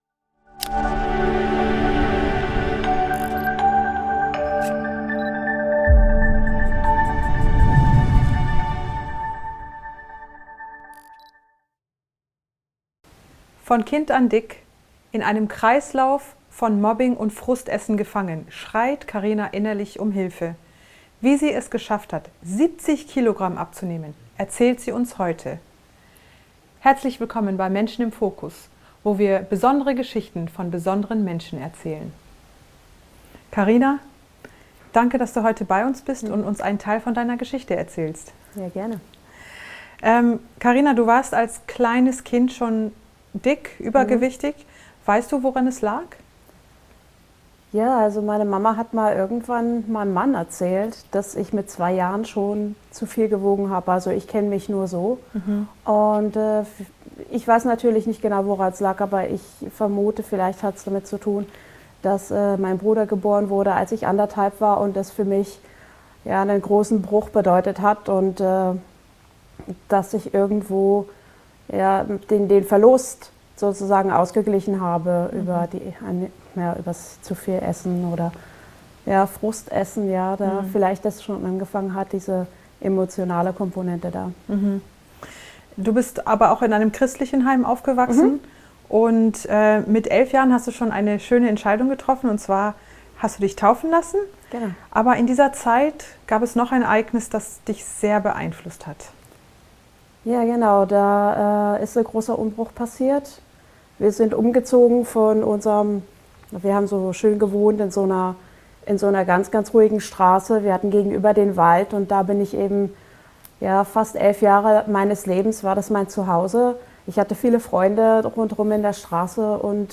Wie sie es geschafft hat 70 kg abzunehmen, erzählt sie in diesem aufschlussreichen Interview.